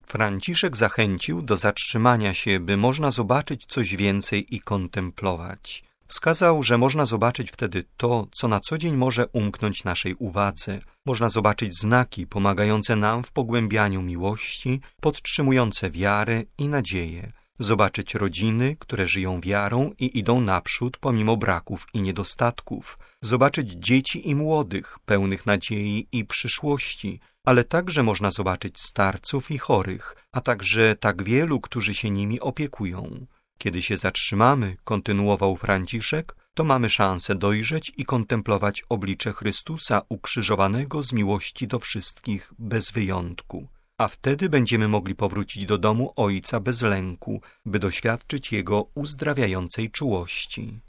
Na trzy działania, które mogą nam pomóc, aby Wielki Post stał się okazją do  zdemaskowania pokusy i by pozwolił naszemu sercu bić zgodnie z rytmem serca Jezusa, zwrócił uwagę Papież w homilii wygłoszonej w czasie Mszy w bazylice św. Sabiny na rozpoczęcie Wielkiego Postu.